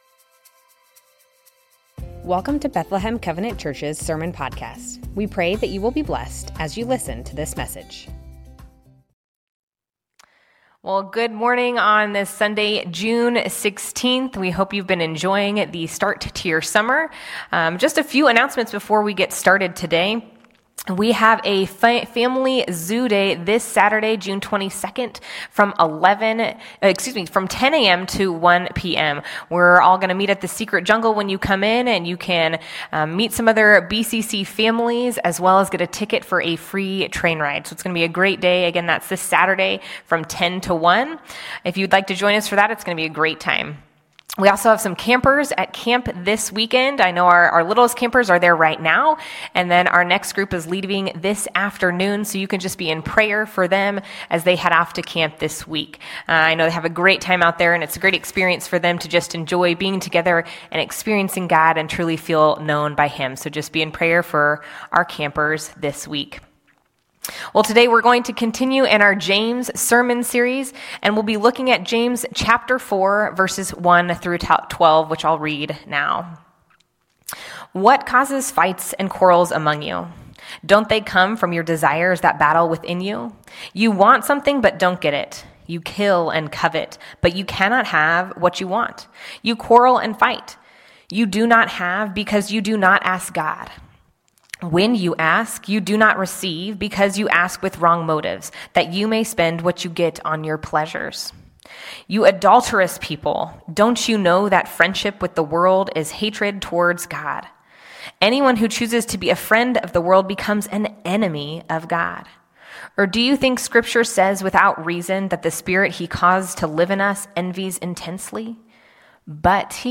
Bethlehem Covenant Church Sermons James - Submitting to God Jun 16 2024 | 00:31:28 1x 00:00 / 00:31:28 Subscribe Share Spotify RSS Feed Share Link Embed